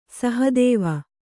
♪ sahadēva